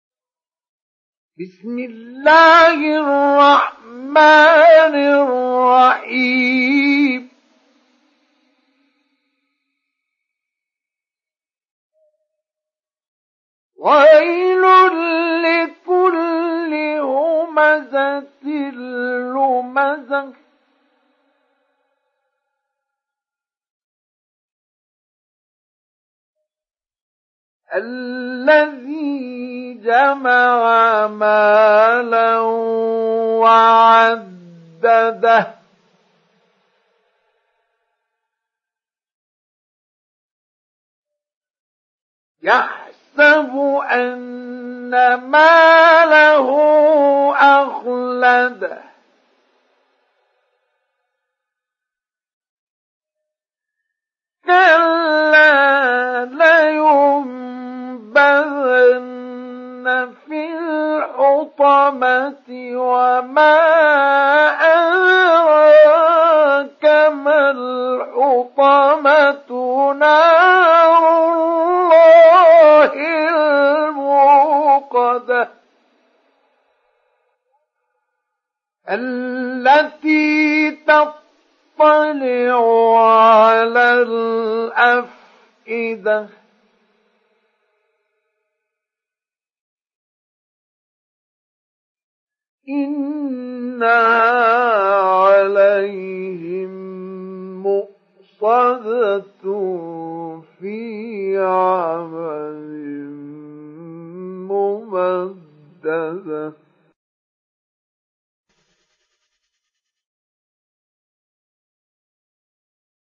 Surat Al Humaza Download mp3 Mustafa Ismail Mujawwad Riwayat Hafs dari Asim, Download Quran dan mendengarkan mp3 tautan langsung penuh
Download Surat Al Humaza Mustafa Ismail Mujawwad